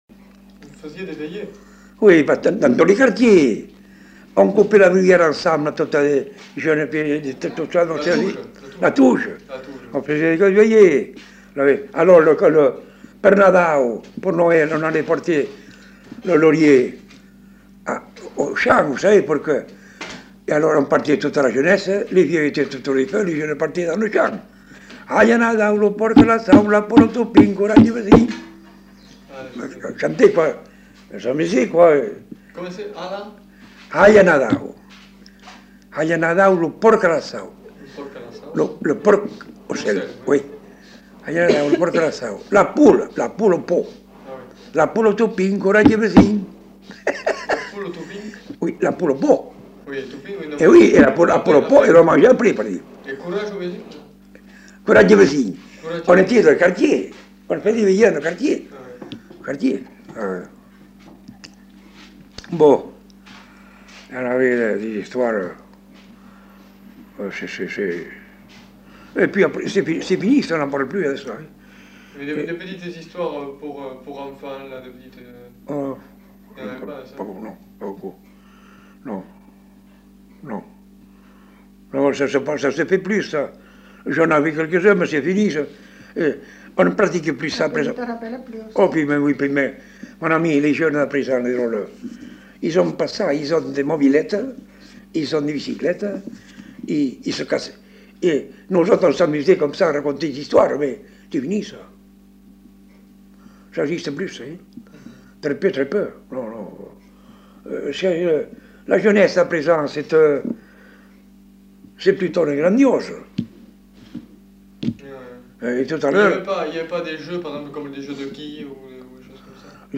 Aire culturelle : Bazadais
Genre : témoignage thématique